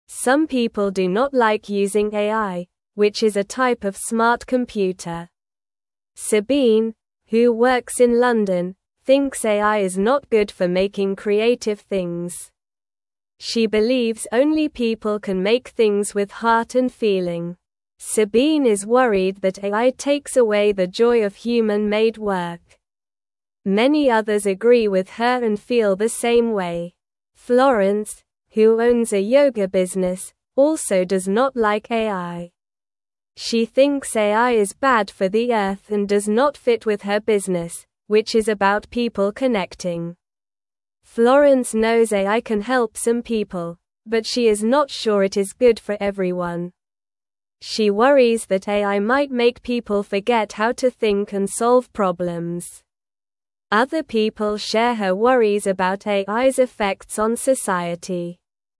Slow
English-Newsroom-Beginner-SLOW-Reading-People-Worry-About-AI-and-Creativity-and-Connection.mp3